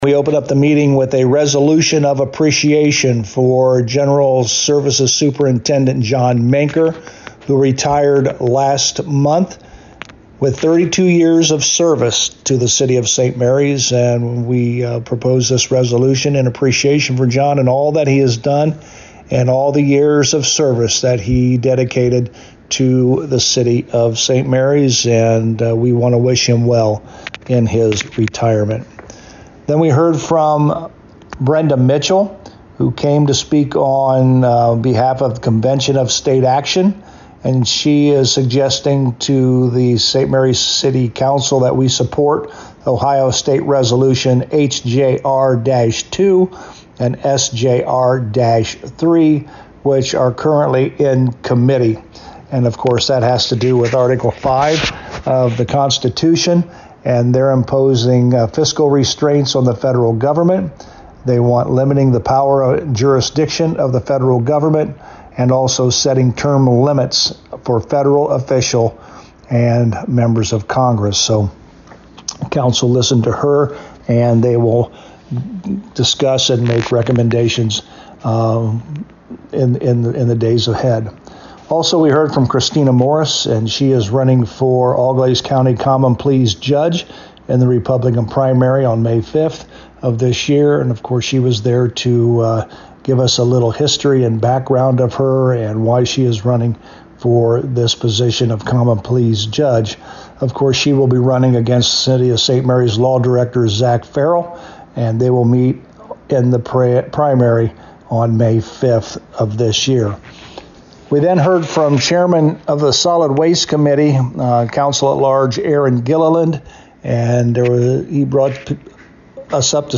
To hear Mayor Hurlburt's Report: